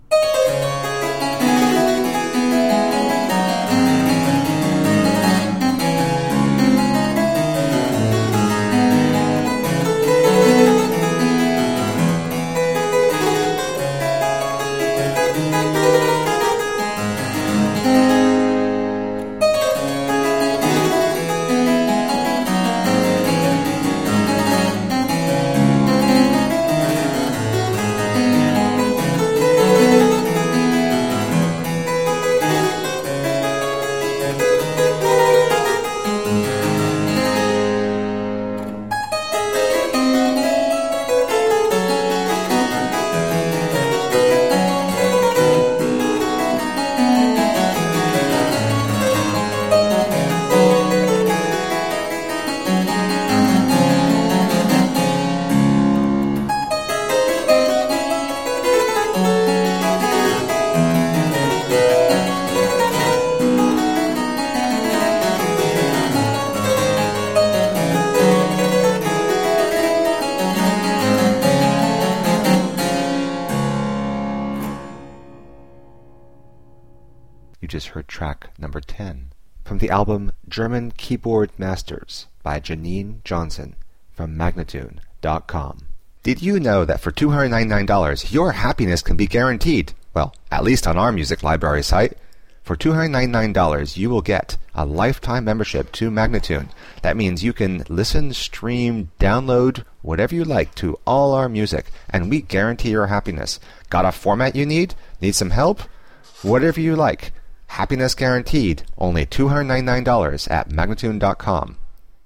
Harpsichord and fortepiano classics.